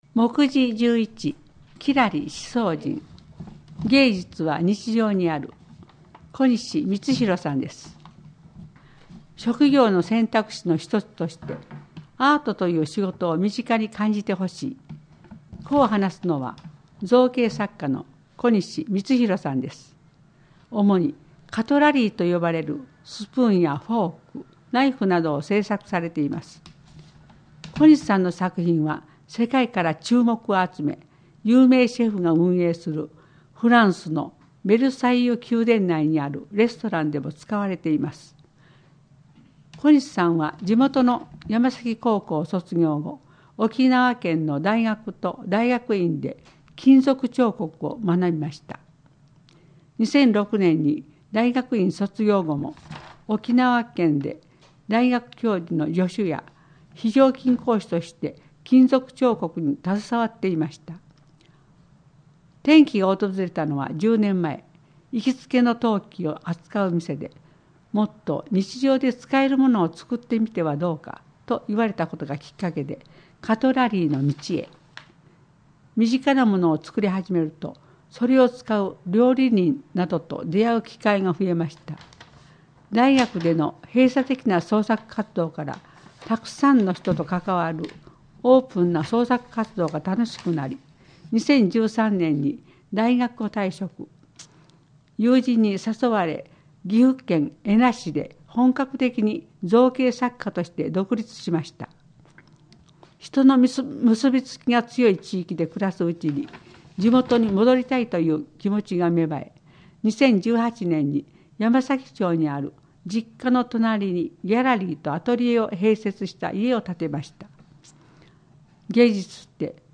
このページでは、広報しそうの記事が録音された「声の広報」が楽しめます。